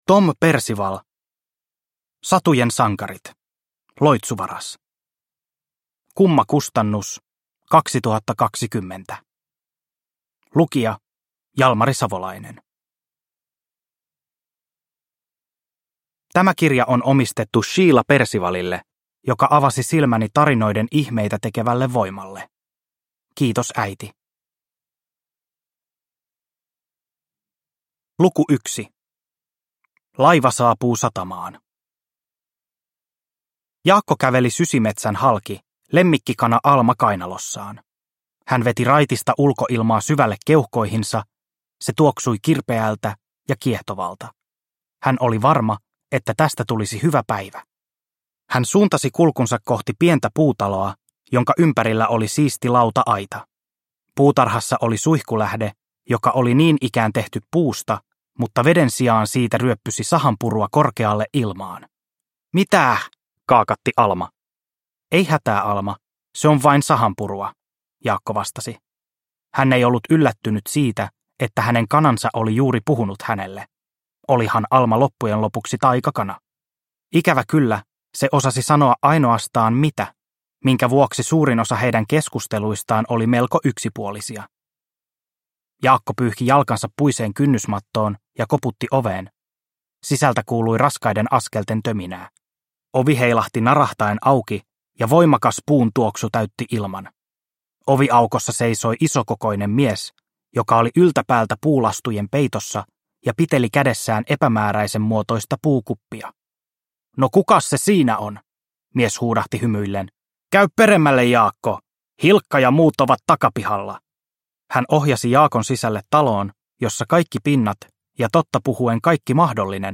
Loitsuvaras – Ljudbok – Laddas ner